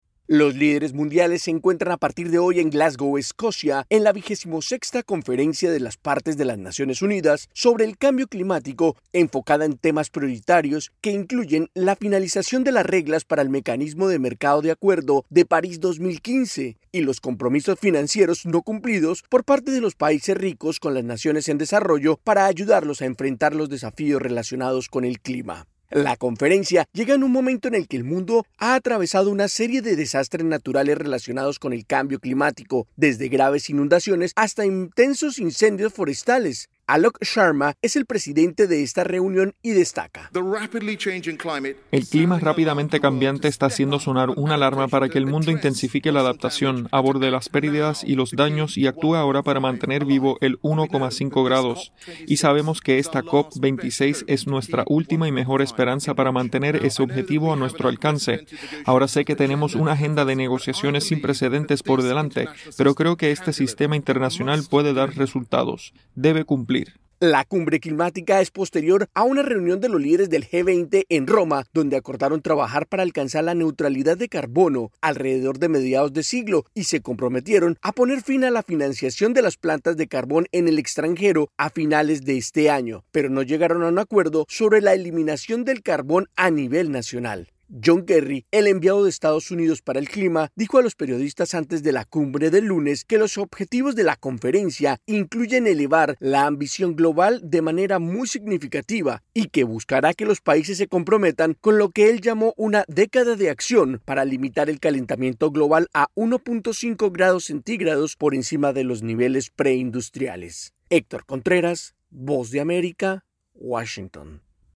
desde la Voz de América en Washington, DC